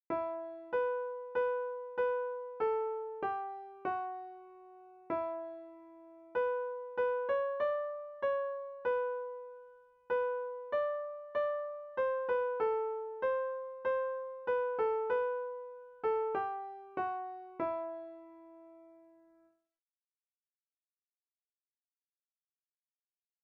Have_mercy_on_me_O_my_God_MIDI.mp3